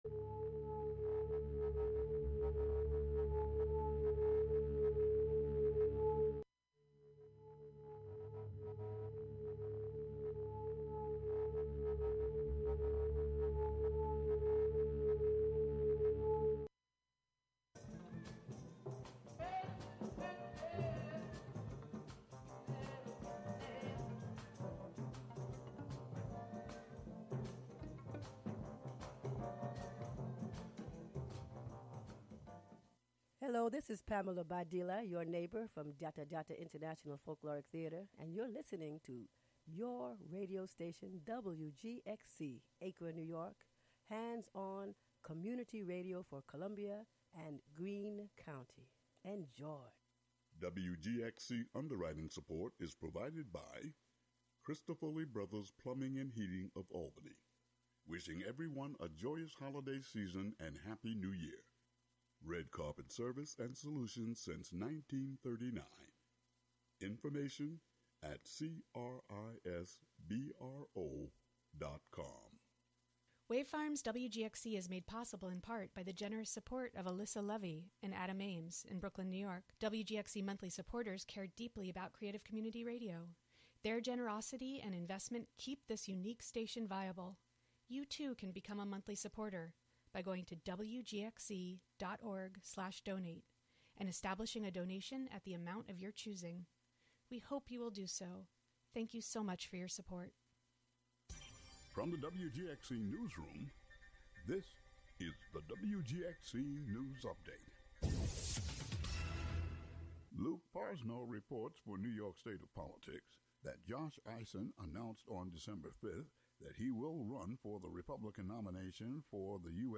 Contributions from many WGXC programmers.
The show is a place for a community conversation about issues, with music, and more.